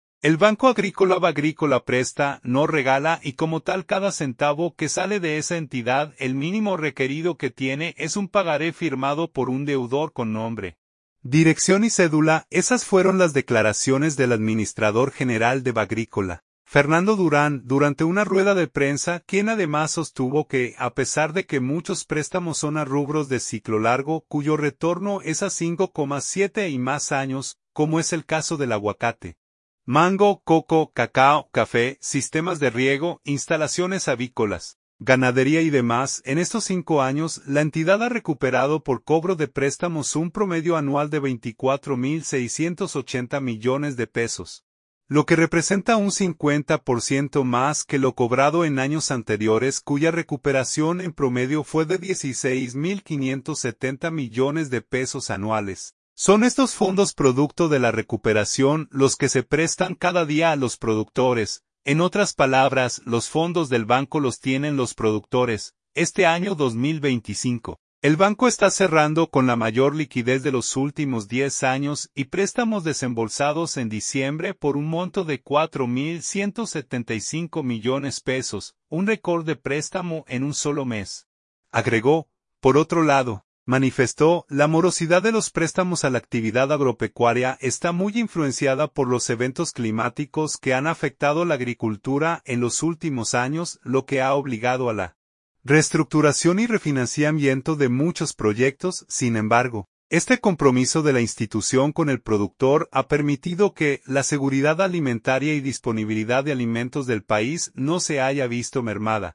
Esas fueron las declaraciones del administrador general de Bagrícola, Fernando Durán, durante una rueda de prensa quien además sostuvo que a pesar de que muchos préstamos son a rubros de ciclo largo, cuyo retorno es a 5, 7 y más años, como es el caso del aguacate, mango, coco, cacao, café, sistemas de riego, instalaciones avícolas, ganadería y demás, en estos 5 años la entidad ha recuperado por cobro de préstamos un promedio anual de 24 mil 680 millones de pesos, lo que representa un 50 % más que lo cobrado en años anteriores cuya recuperación en promedio fue de 16 mil 570 millones de pesos anuales.